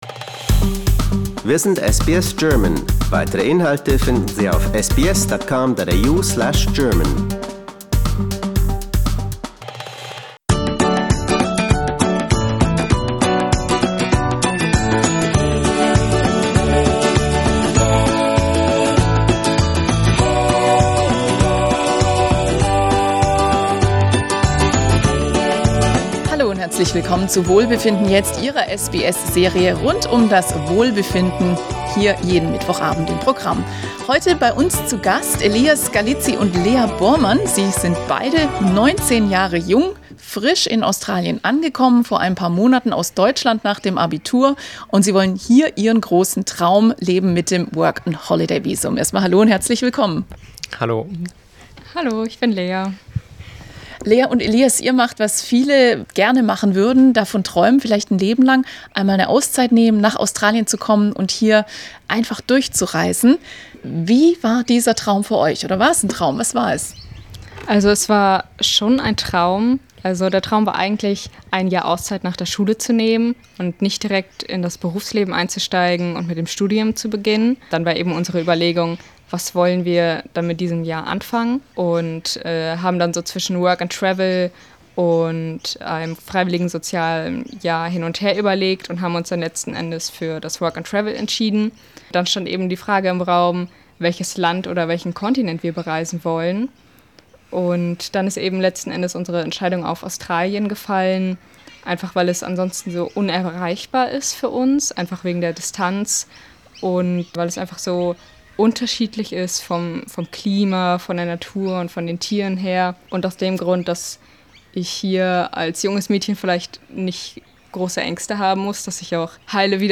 Wir fragen das junge Paar wo es ihnen am besten gefällt und worauf sie sich am meisten freuen.